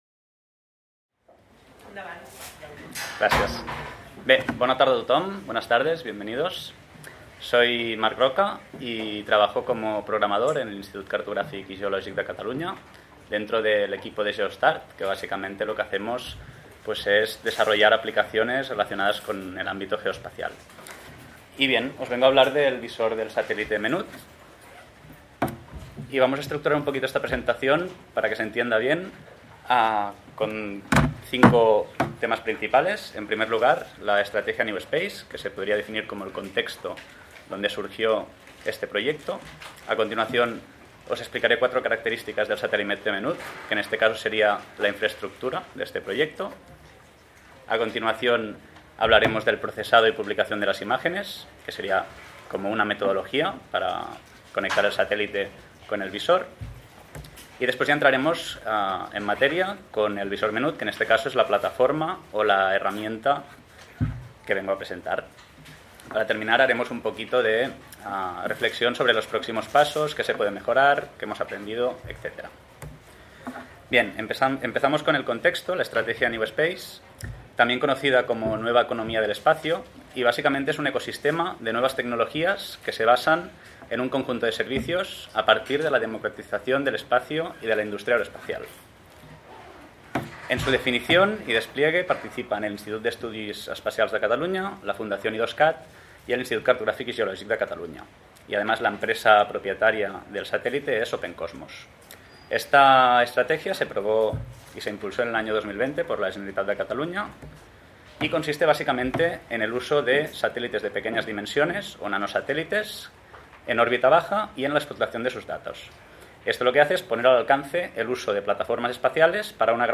En les 18enes Jornades de SIG Lliure 2025, organitzades pel SIGTE de la Universitat de Girona